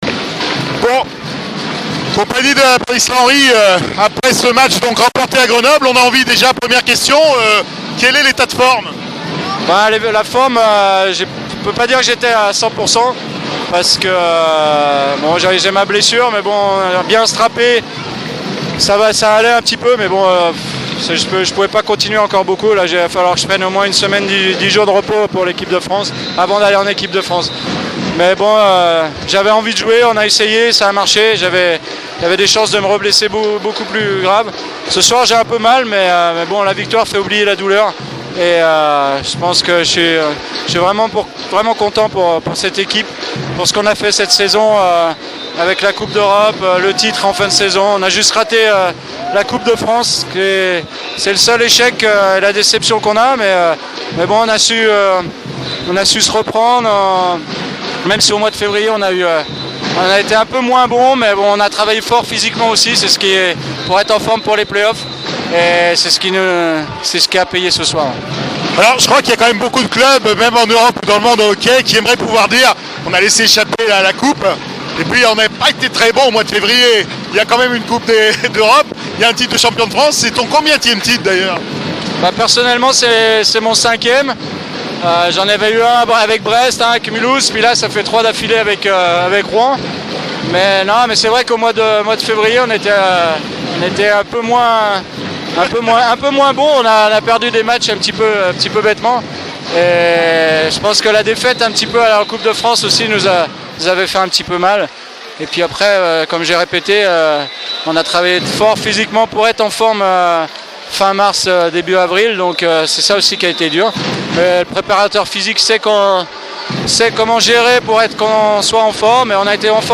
Interview sur la glace après le dernier match de la saison